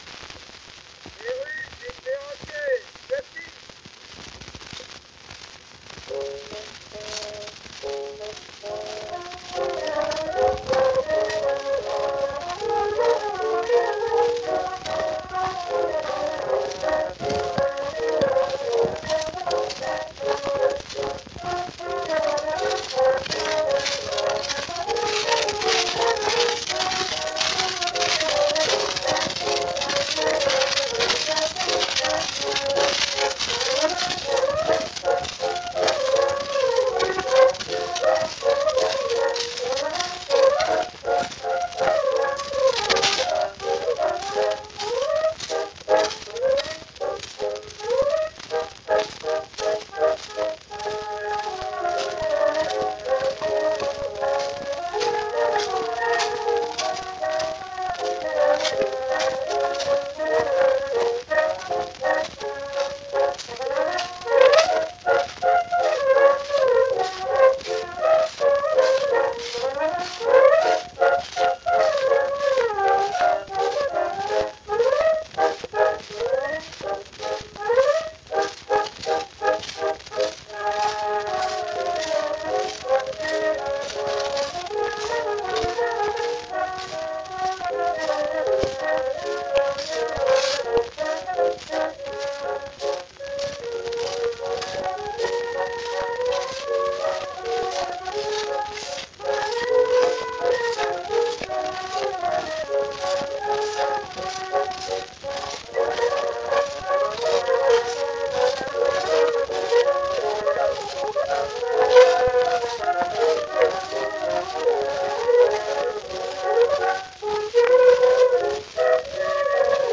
Sie spielten bei der Aufnahme typischerweise in gleichmäßig hoher Lautstärke.
Unbekannte Bläsergruppe: Perruche et perroquet, Schottish (Albert Corbin).
Perruche-et-perroquet-Scottish.mp3